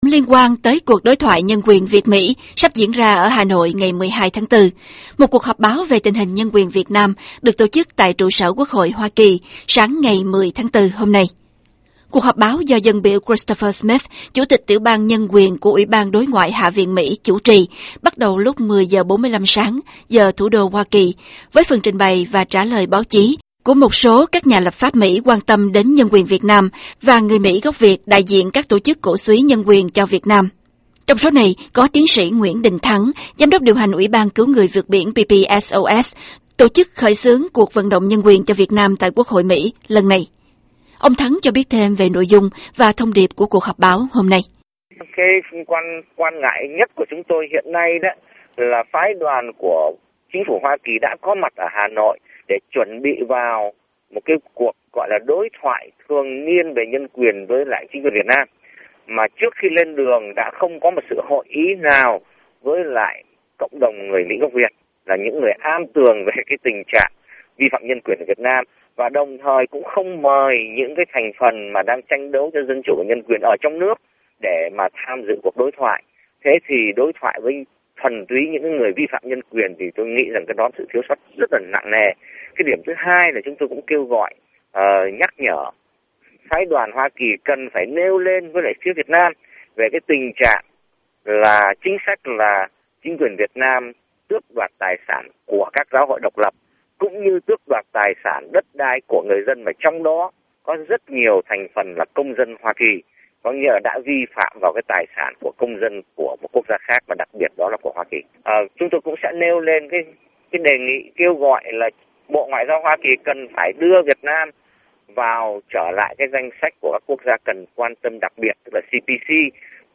Cuộc trao đổi này được thực hiện nửa tiếng trước khi buổi họp báo diễn ra ở Quốc hội.